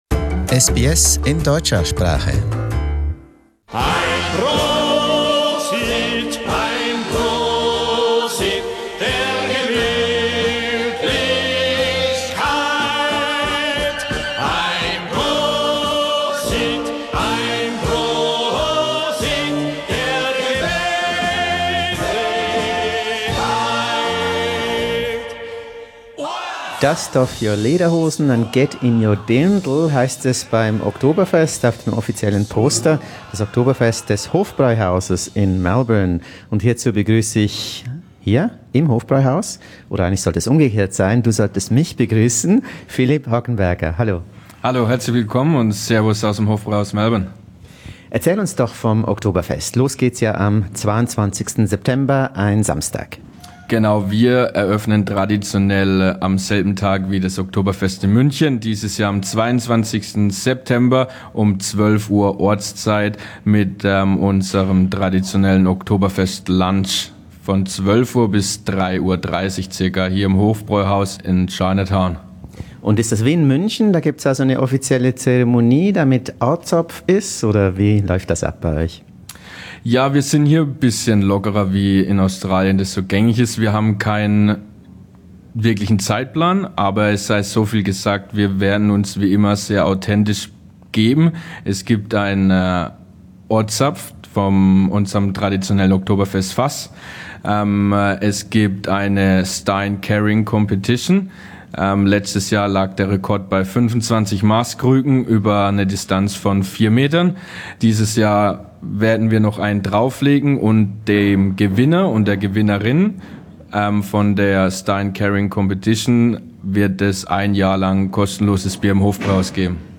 Wie gefeiert wird, erfuhren wir bei einem SBS-Ortsbesuch.